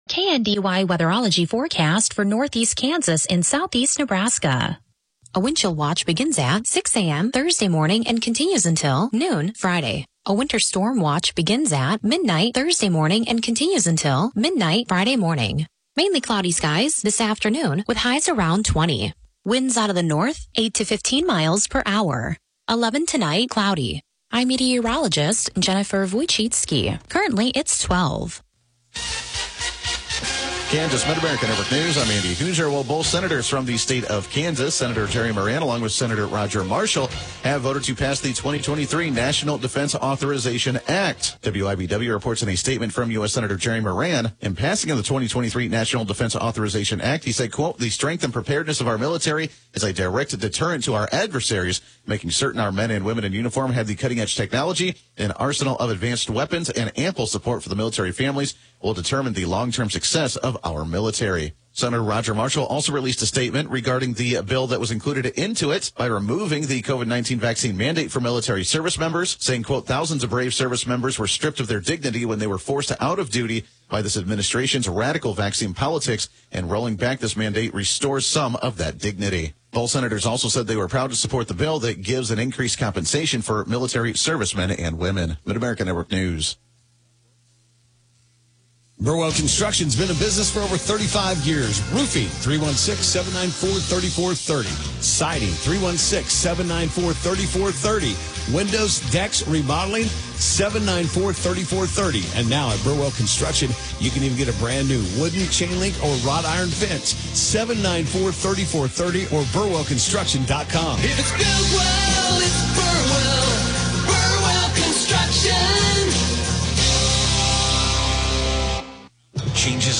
The KNDY News: Midday Edition podcast gives you local, regional and state news as well as relevant information for your farm or home as well. Broadcasts are archived daily as originally broadcast on Classic Country AM 1570/FM 94.1 KNDY.